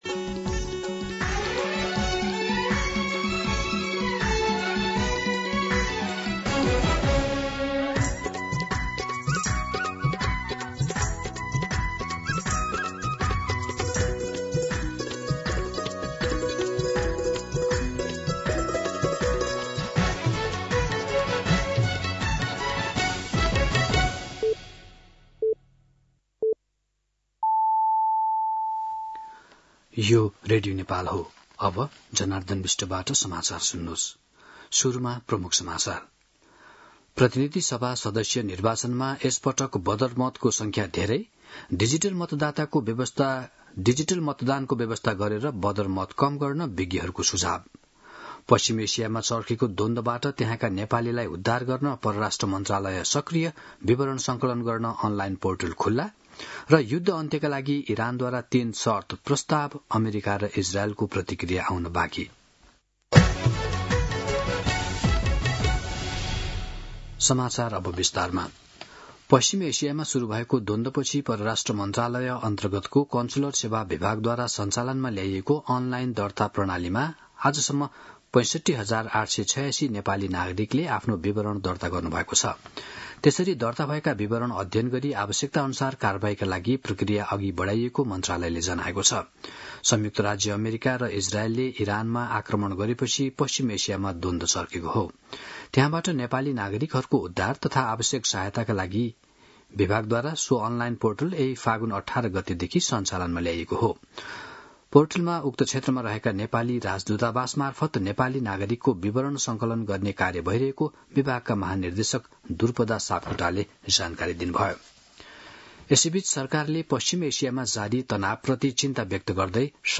दिउँसो ३ बजेको नेपाली समाचार : २८ फागुन , २०८२